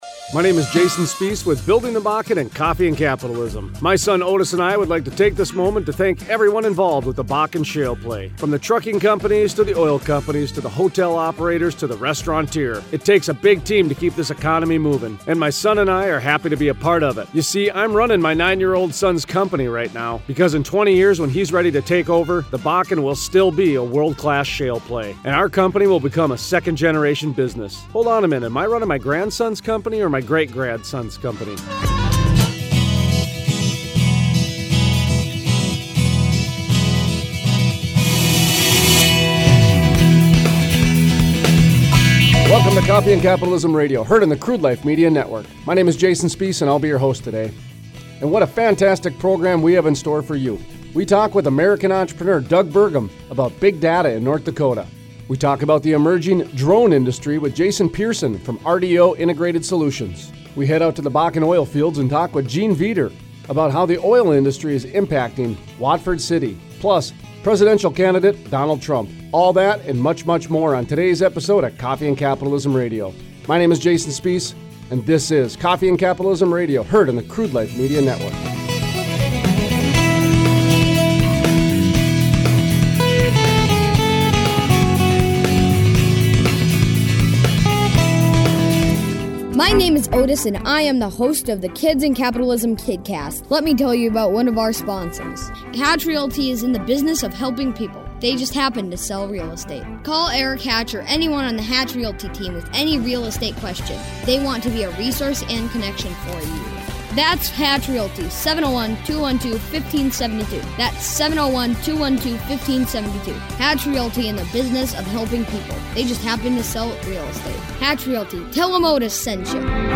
Interview: Doug Burgum, founder, Kilbourne Group, and ND Gubernatorial Candidate